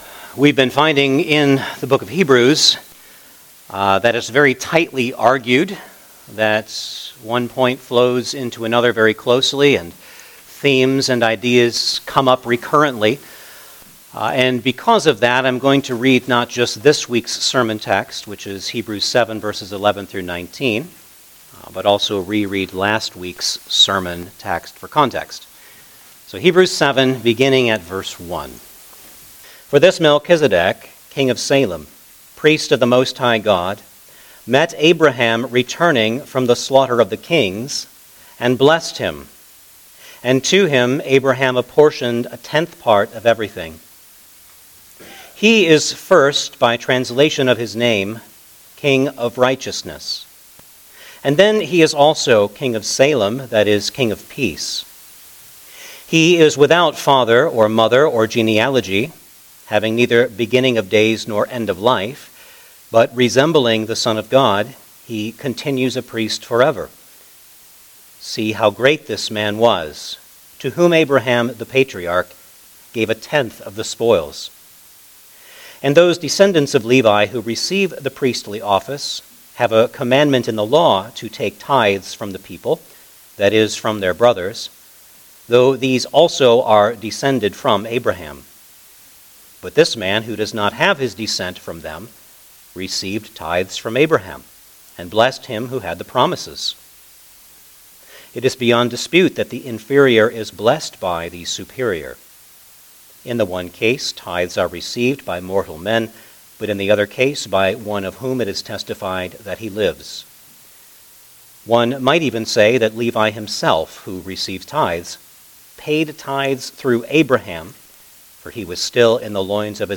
Hebrews Passage: Hebrews 7:11-19 Service Type: Sunday Morning Service Download the order of worship here .